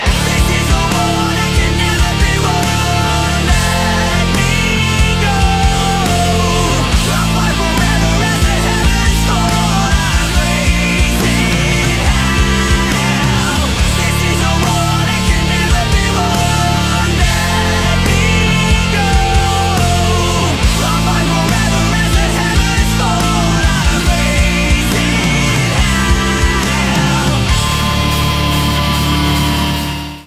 • Качество: 192, Stereo
Весьма неплохая песня в жанре рок